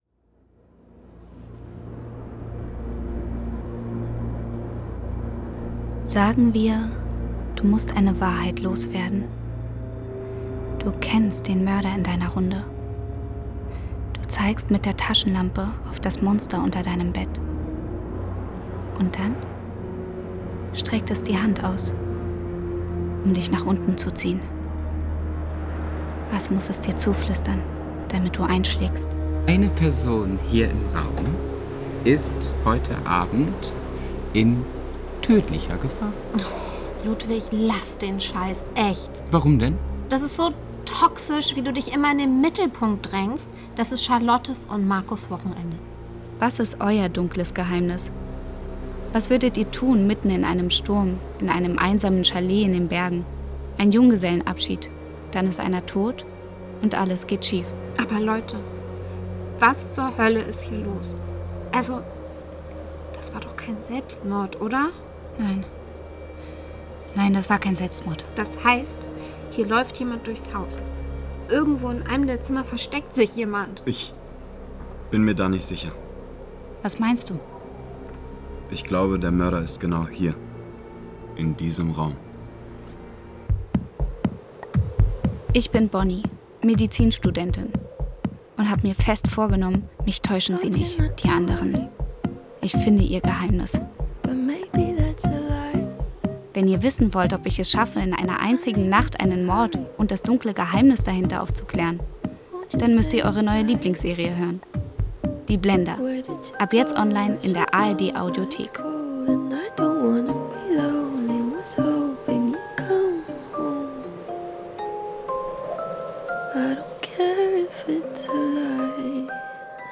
Die Blender – Trailer zur Crime-Hörspiel-Serie
die-blender-hoerspielpodcast-trailer.mp3